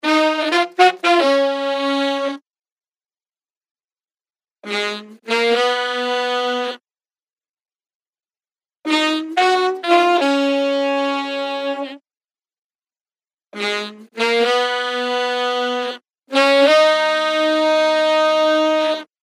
Here you can listen to the saxes that come out in the end:
NonVolete-sax.mp3
Seattle Youth Jazz Ensamble : brass